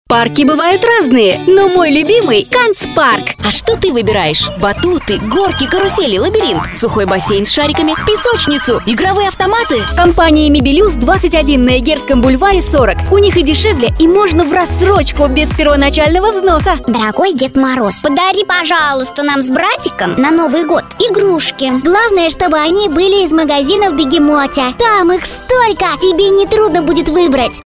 Тракт: микрофон Октава МК-219, Звуковая карта Focusrite Scarlett 2i2, звукоизолированная кабина для записи.